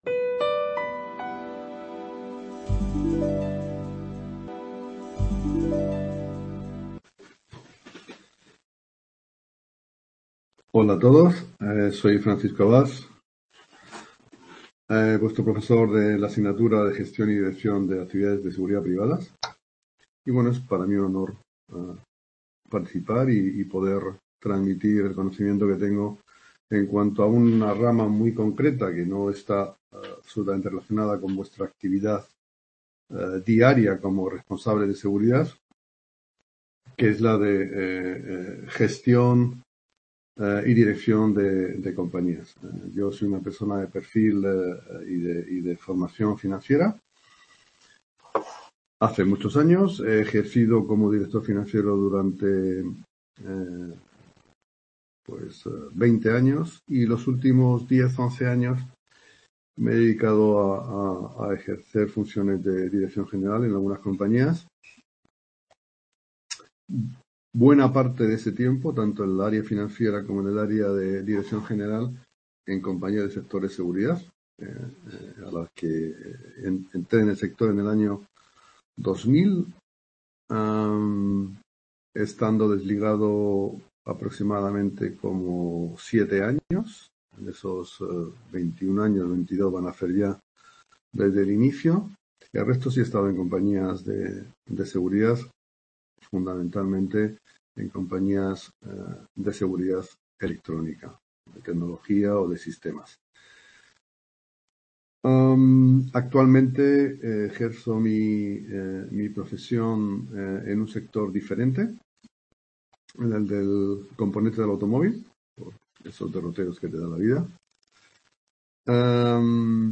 Ponencia